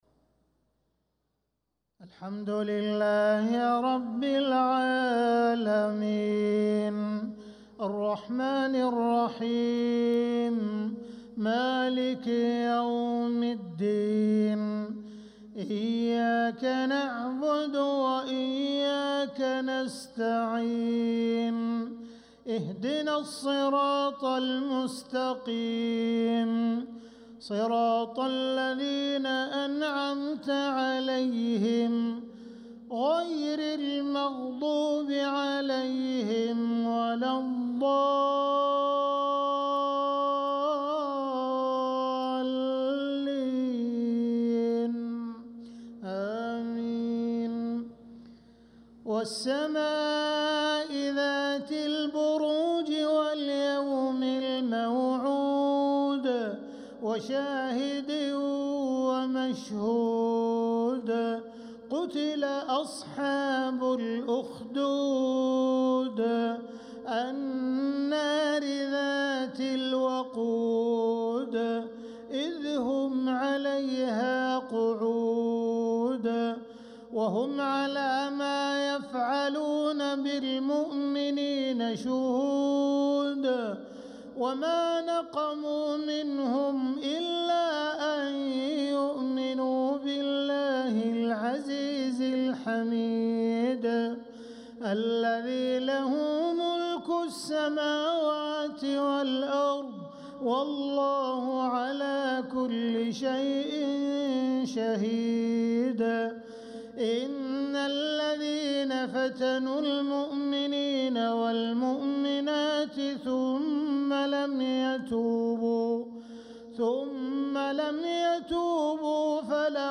صلاة العشاء للقارئ عبدالرحمن السديس 18 ربيع الأول 1446 هـ
تِلَاوَات الْحَرَمَيْن .